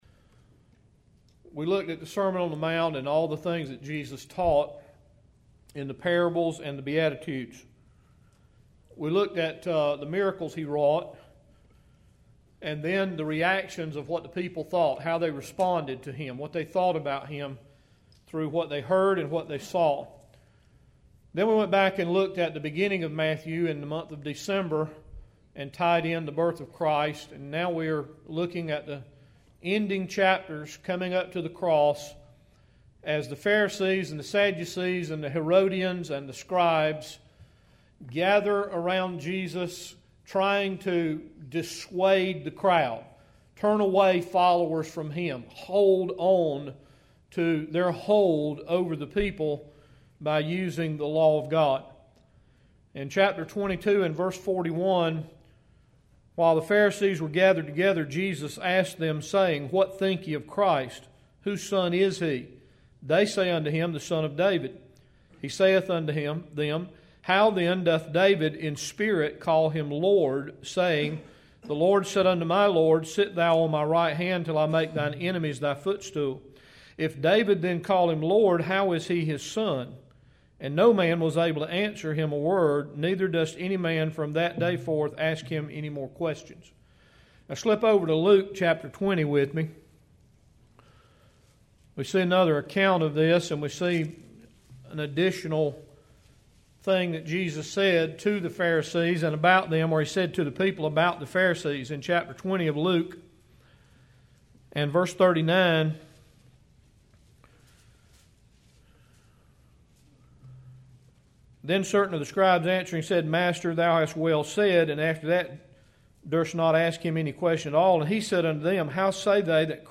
7-8-12AMmessage.mp3